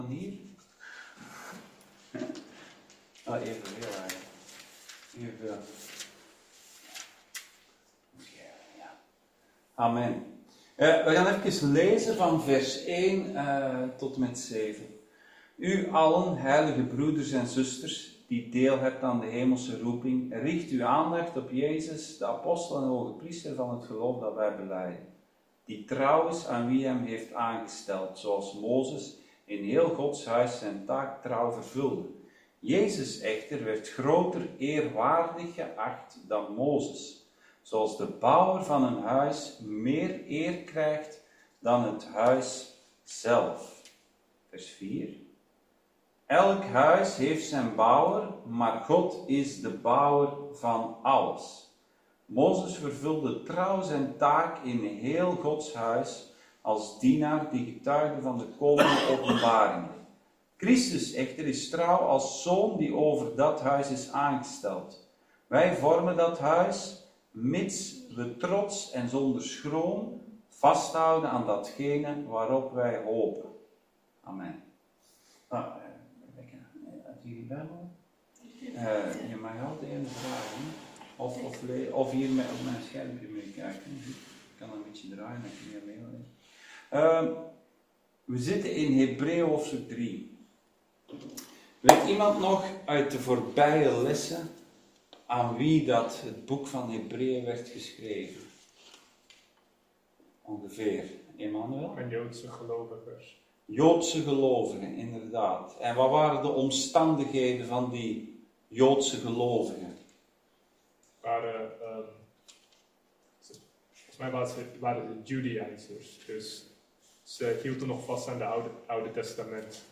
Hebreeën Dienstsoort: Bijbelstudie « De Tabernakel